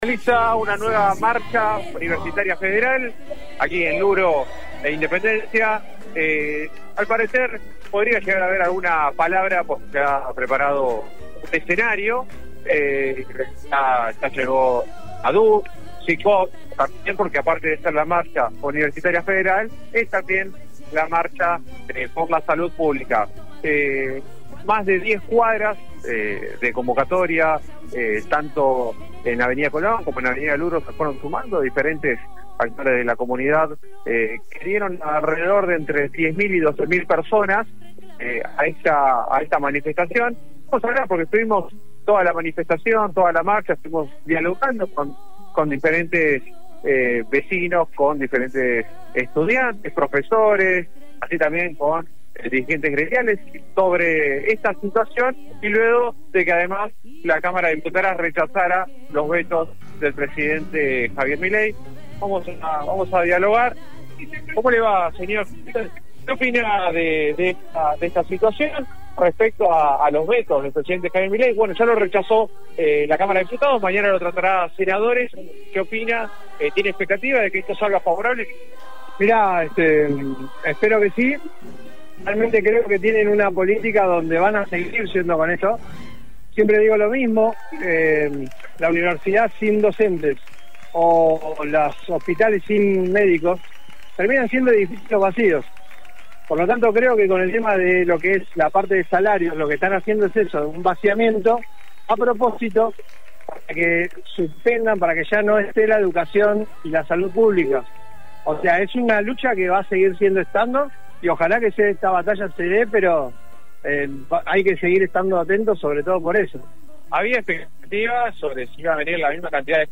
Radio 10 Mar del Plata transmitió en vivo la movilización que recorrió el centro de la ciudad con sindicatos, estudiantes, jubilados y organizaciones sociales.
• Testimonio de una jubilada:
• Manifestante en Peña e Independencia: